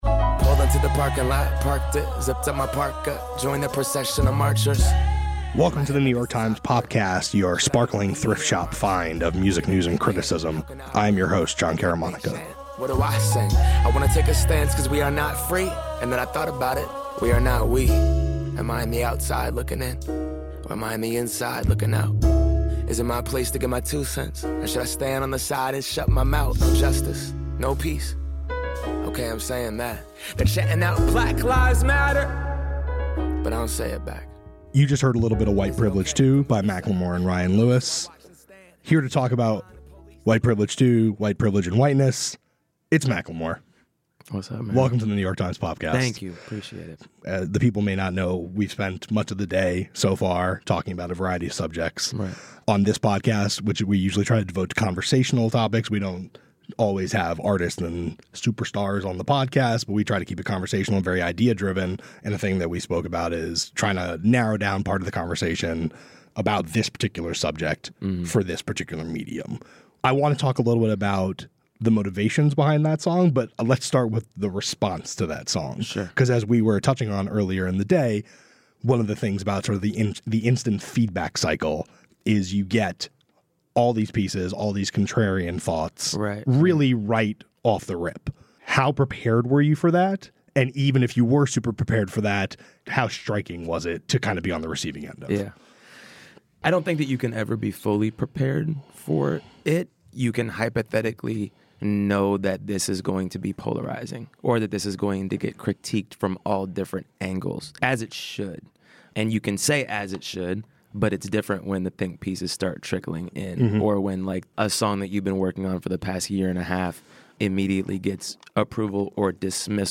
Jon Caramanica chats with the rapper about the role of music in creating change.